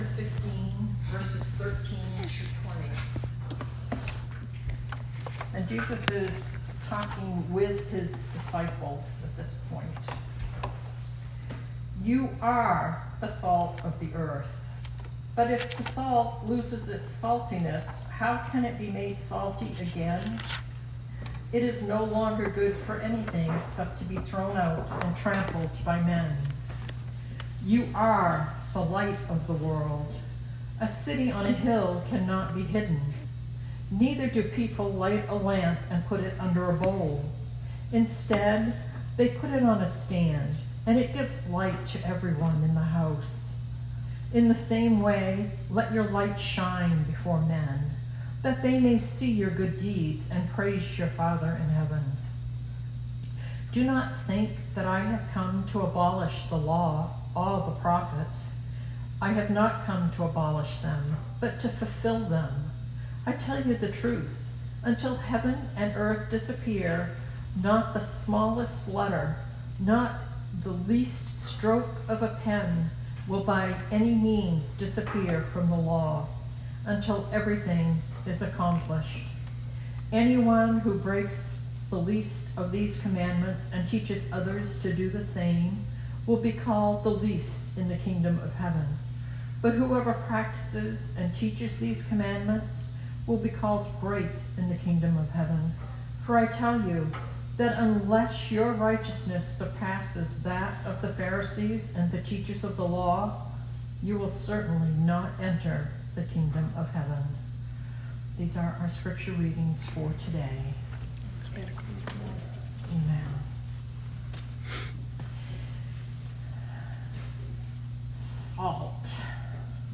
Sermon 2020-02-09